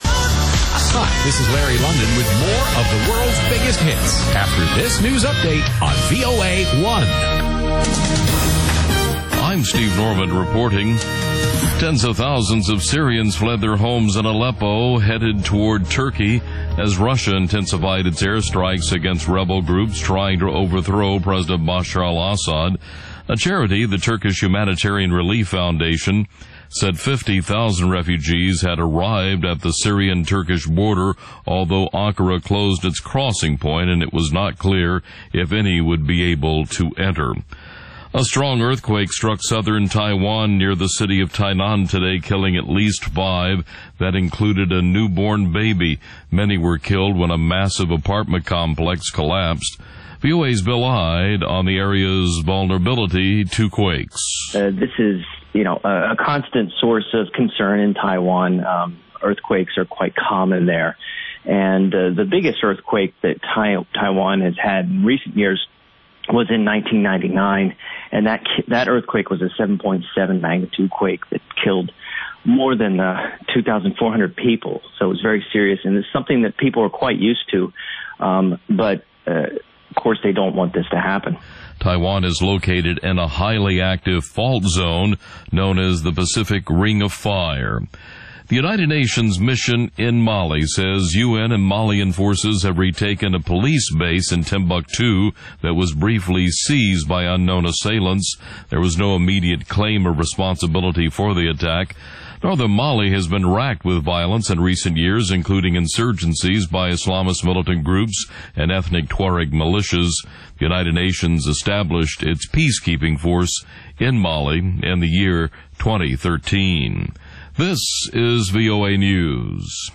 ყოველ შაბათს რადიო თავისუფლების პირდაპირ ეთერში შეგიძლიათ მოისმინოთ სპორტული გადაცემა „მარათონი“.